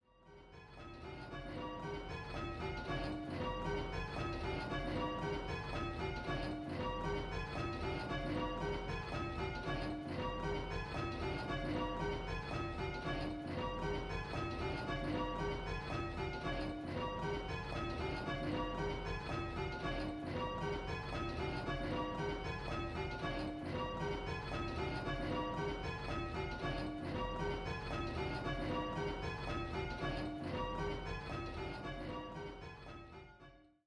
5 & 6 Bell Game